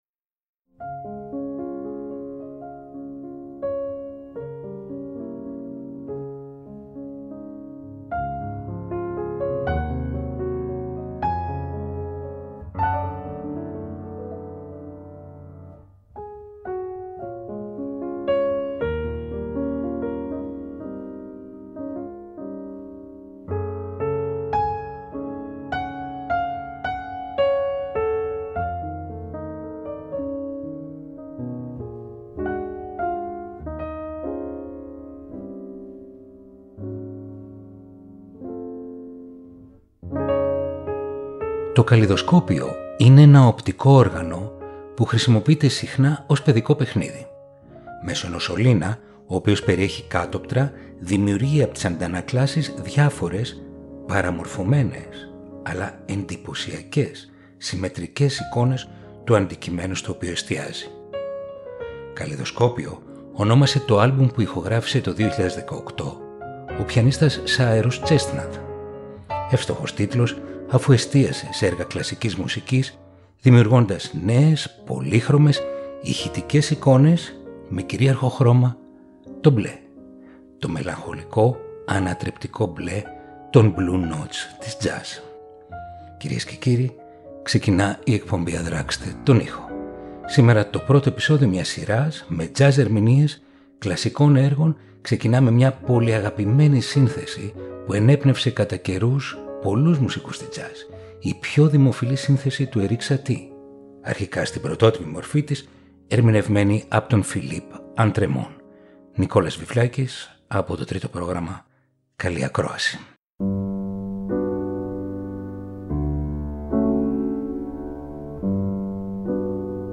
Η τζαζ συναντά την κλασσική μουσική
Τα μεταμορφώνουν με ευφάνταστες αρμονίες, απρόσμενους αυτοσχεδιασμούς, νέους ρυθμούς και ηχοχρώματα.